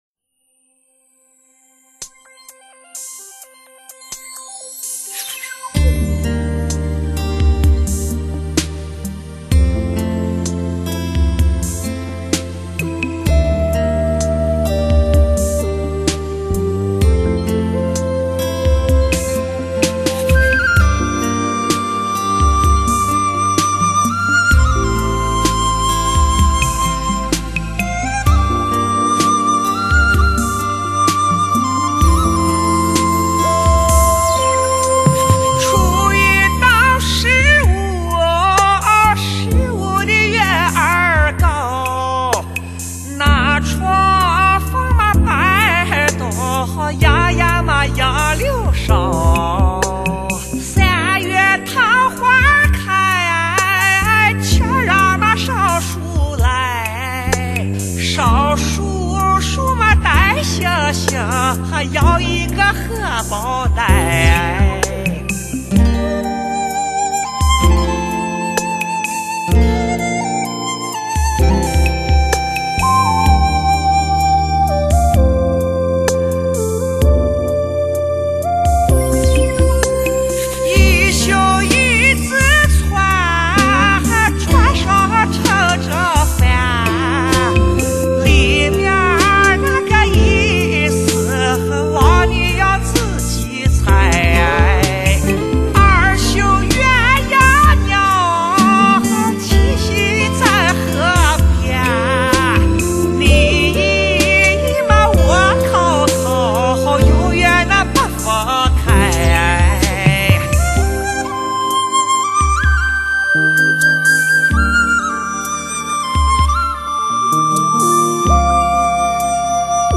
男声版